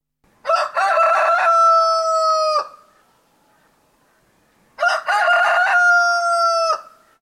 crow-sounds